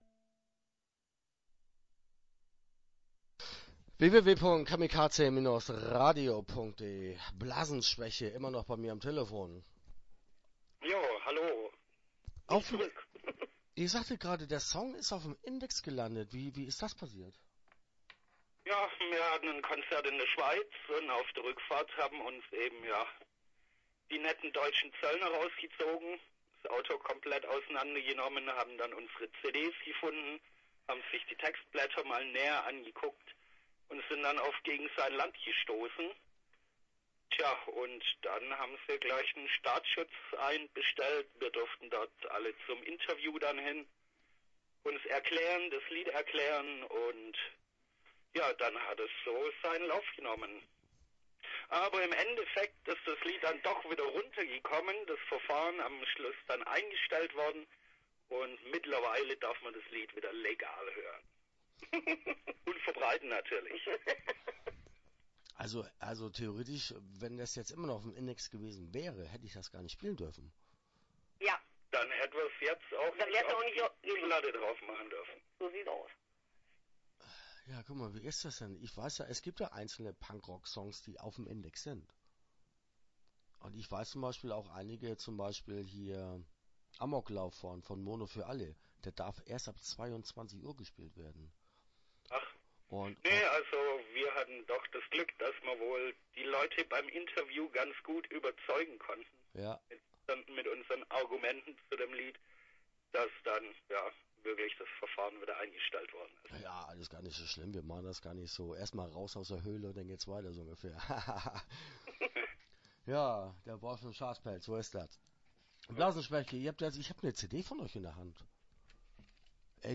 Blasenschwäche - Interview Teil 1 (10:29)